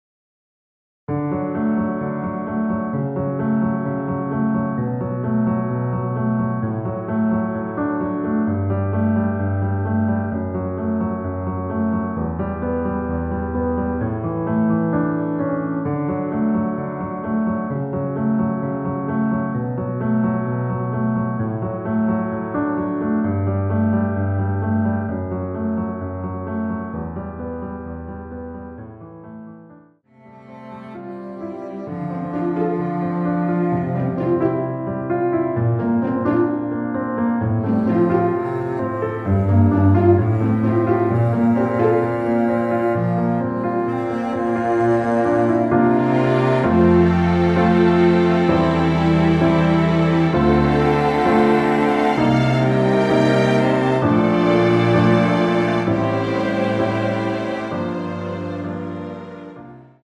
원키에서(+2)올린 MR입니다.
D
앞부분30초, 뒷부분30초씩 편집해서 올려 드리고 있습니다.
중간에 음이 끈어지고 다시 나오는 이유는